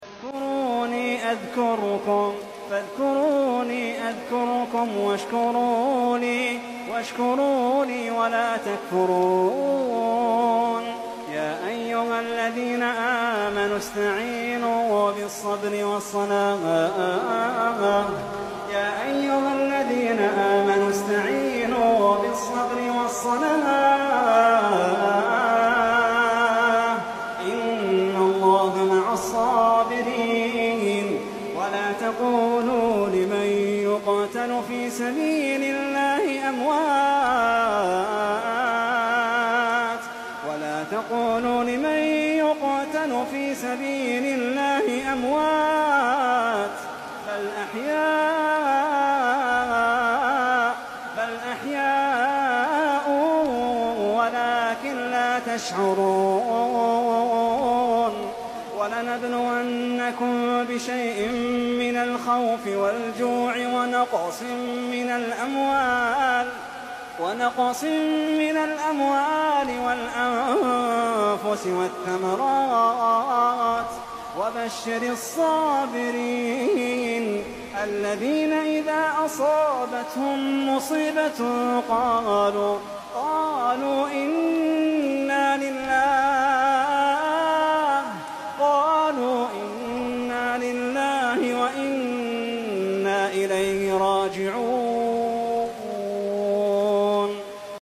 ماتيسر من سورة(البقرة) بصوت الشيخ